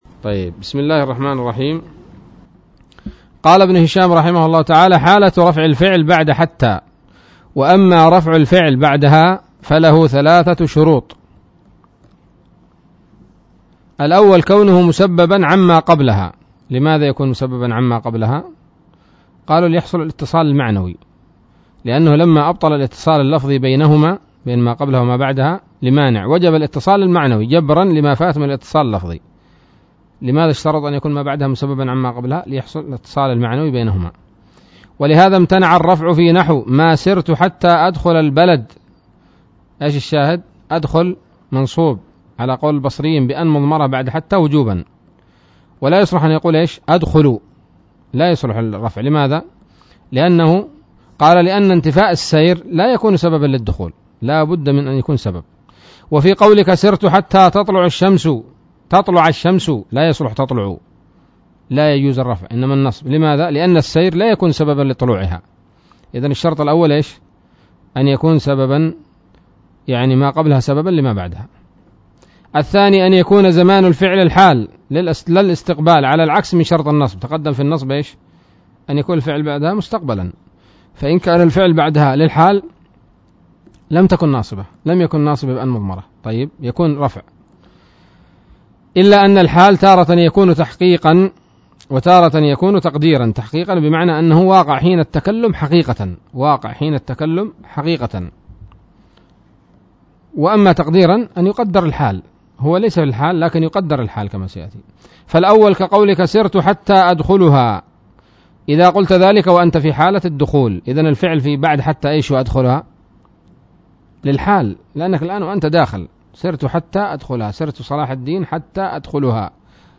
الدرس الرابع والثلاثون من شرح قطر الندى وبل الصدى